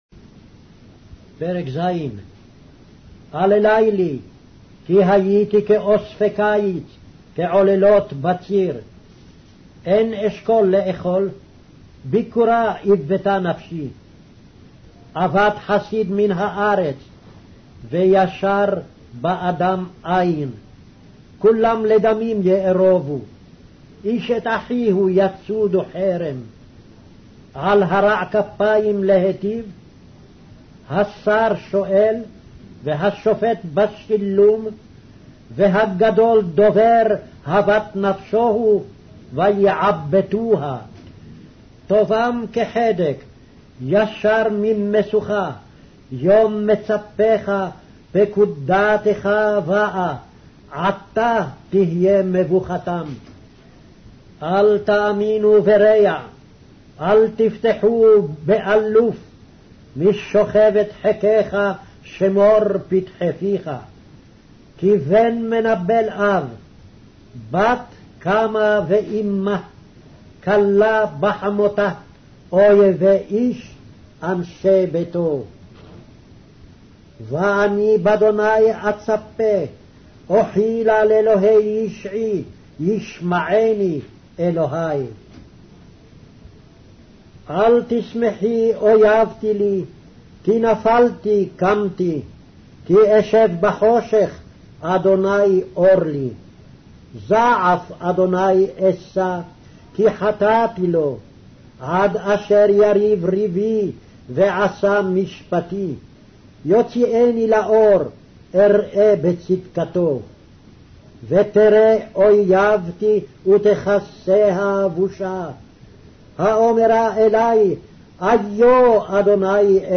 Hebrew Audio Bible - Micah 4 in Irvmr bible version